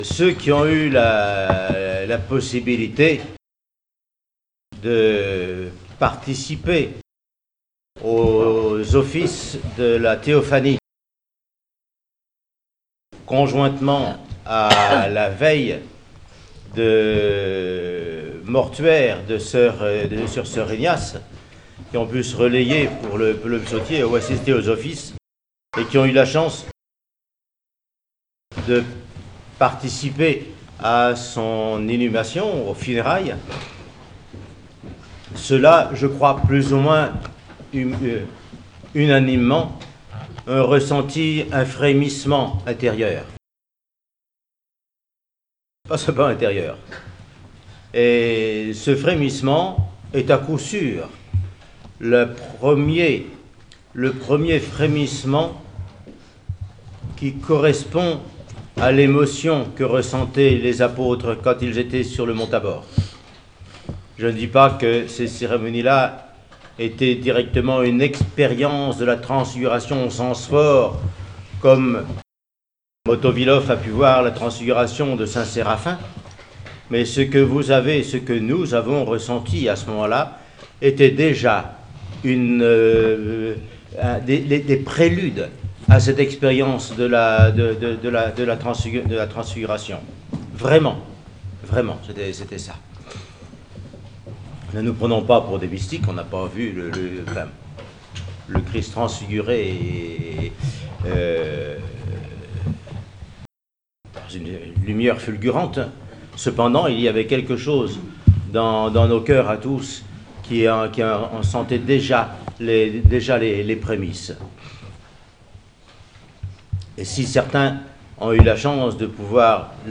Monastère de la Transfiguration. Homélie sur la maladie et la mort.